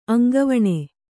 ♪ aŋgavaṇe